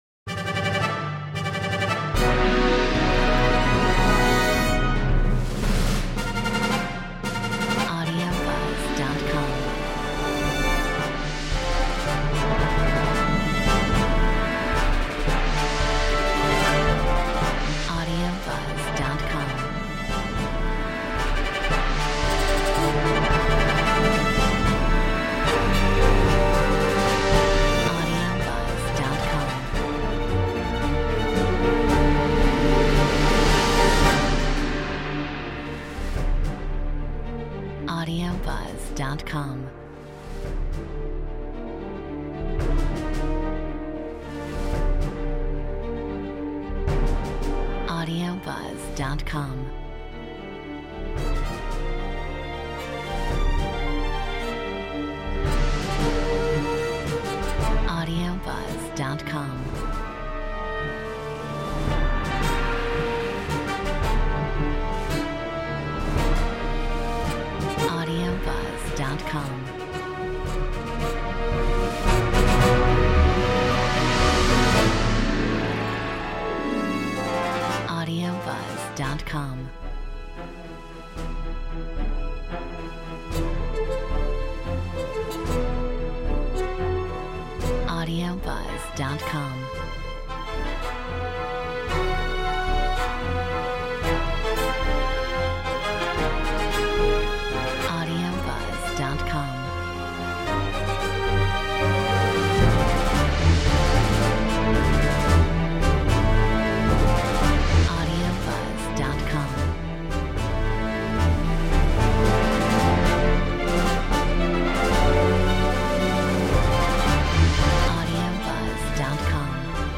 Metronome 112